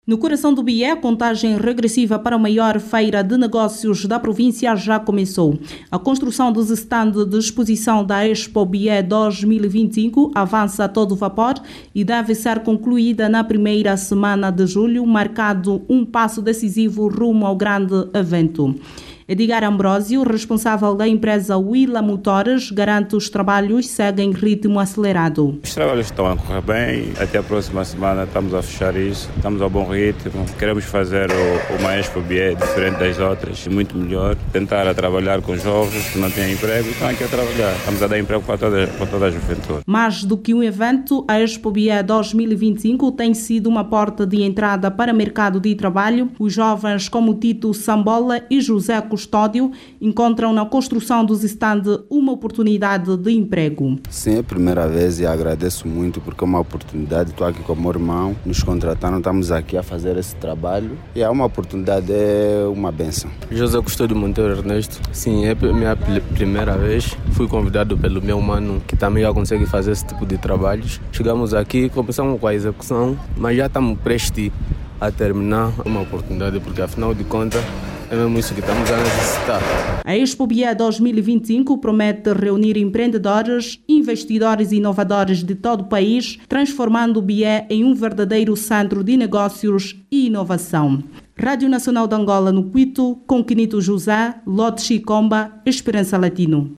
O evento, que prevê reunir produtores e empresários nacionais, promete transformar o Cuíto num centro de negócios e dinamismo económico do país, além de permitir a criação de novos postos de trabalho. Jornalista